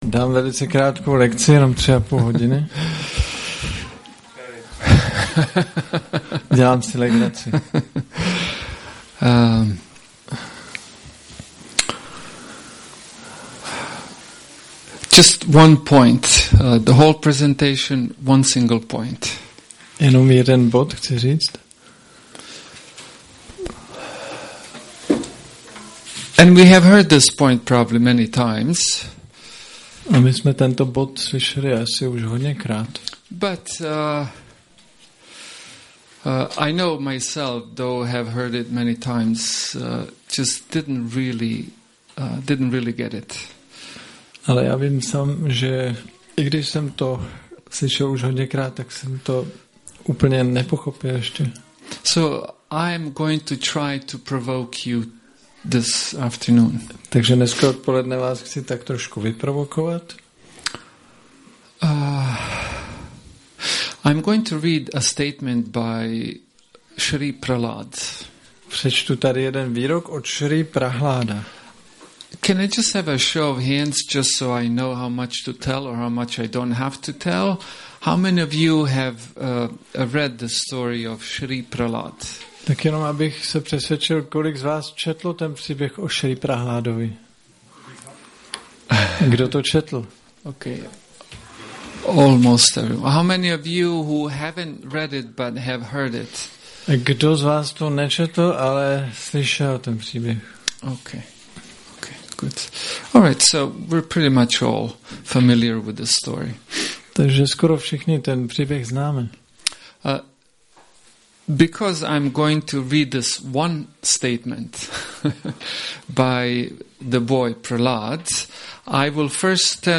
Přednáška Nedělní program